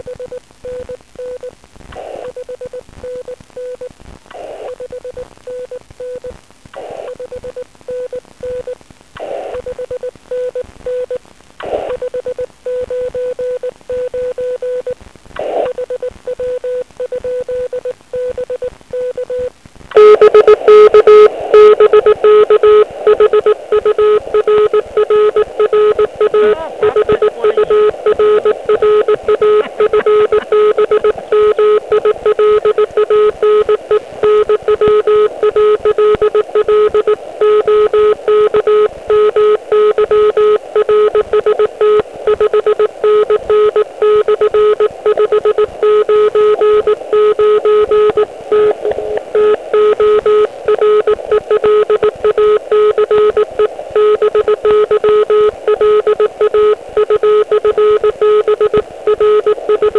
Provedli jsme vlastně spojení ještě za velice "hutné" vrstvy D a to odrazem od F1. Útlum tedy musel být obrovský.